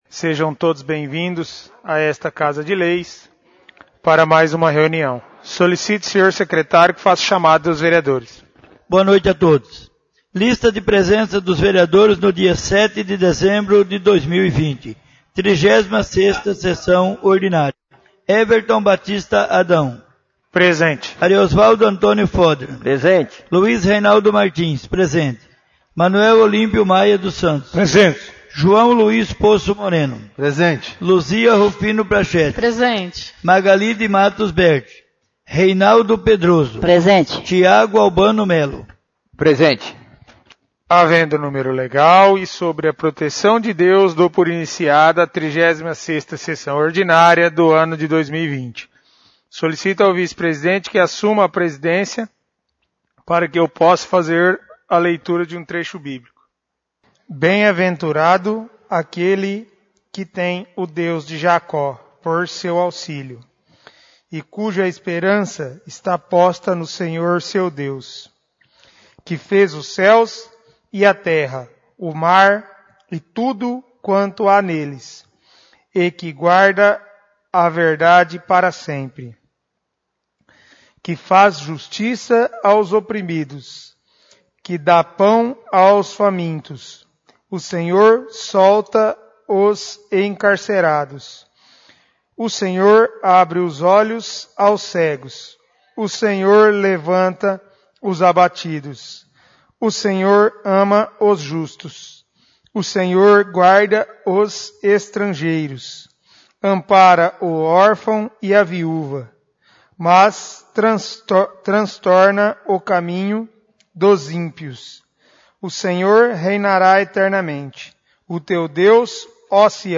36° Sessão Ordinária